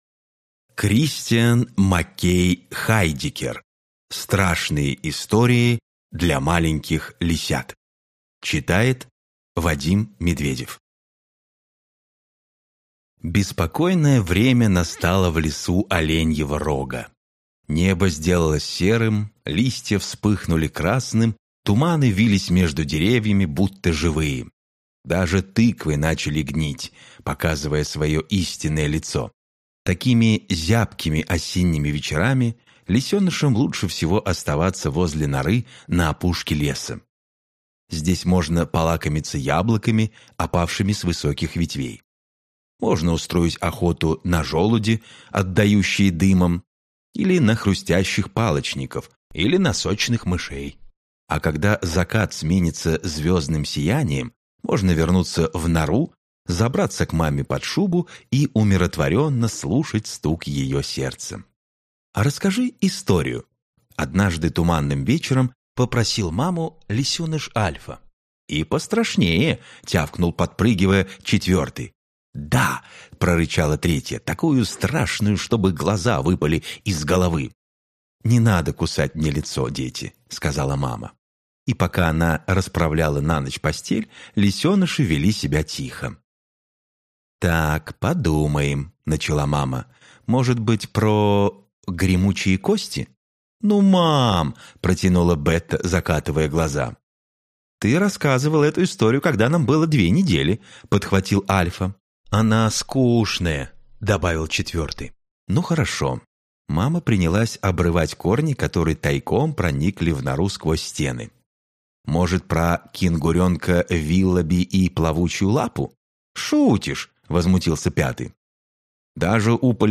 Аудиокнига Страшные истории для маленьких лисят | Библиотека аудиокниг